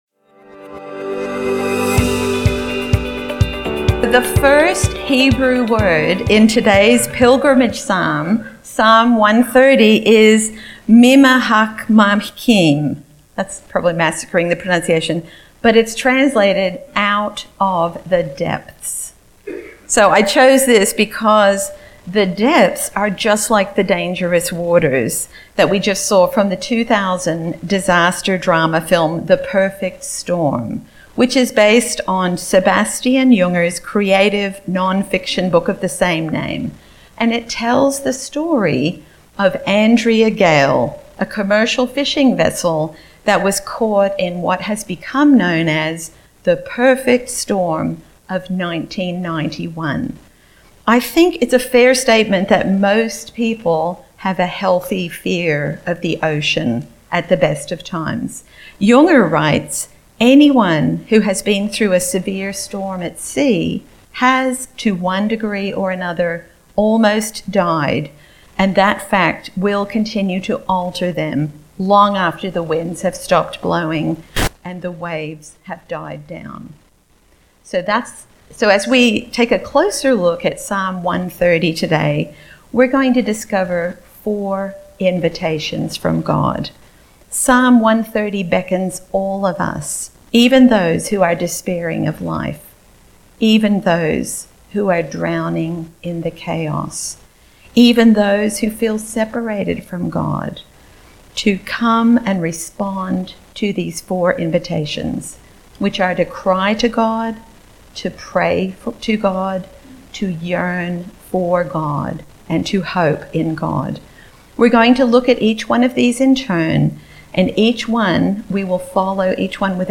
opening movie clip cry to God pray to God yearn for God hope in God